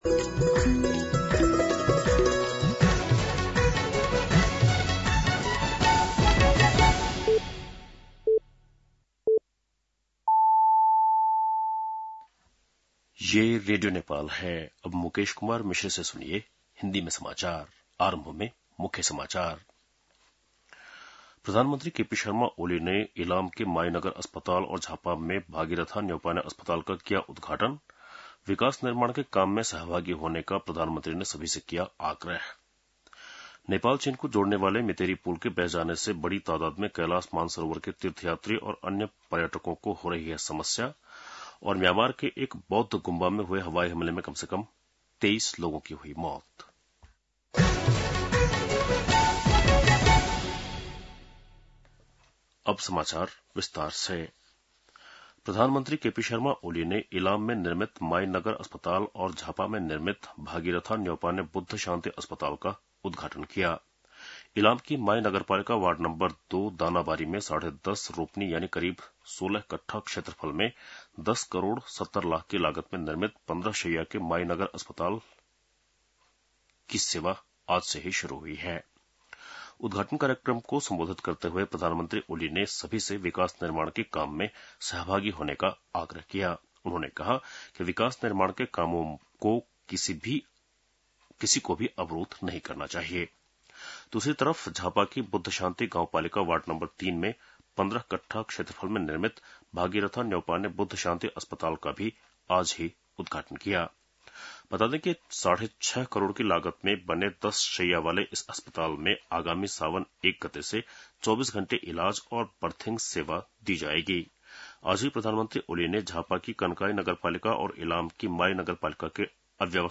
An online outlet of Nepal's national radio broadcaster
बेलुकी १० बजेको हिन्दी समाचार : २८ असार , २०८२